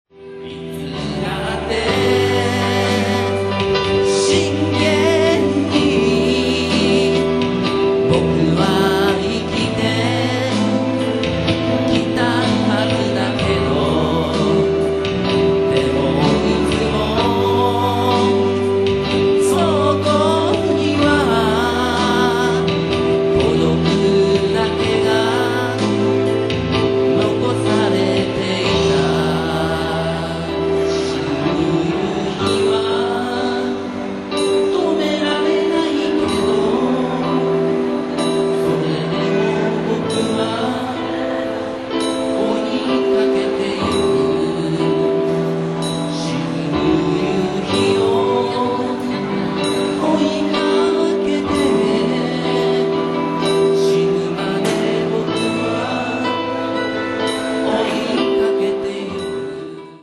マイカル茨木のイベントコーナーで吹き抜けで広くて明るくて、もう･･･。